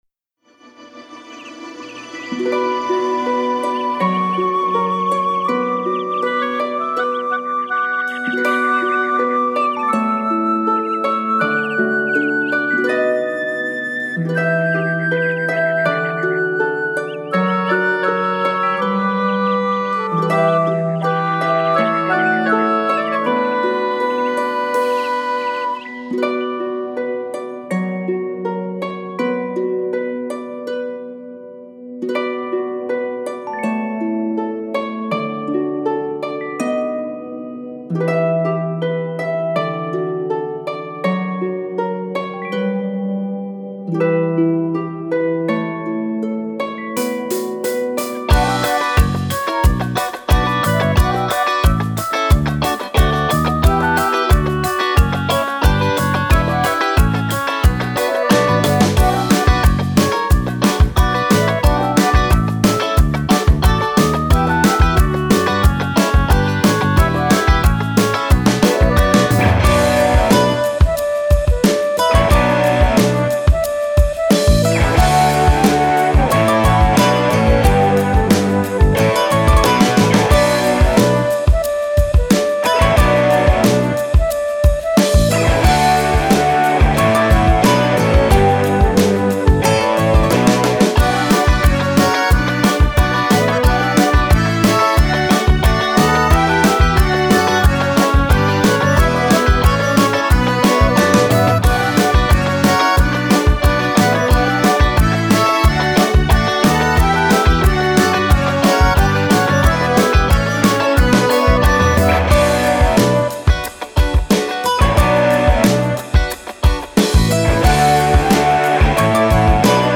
Фонограмма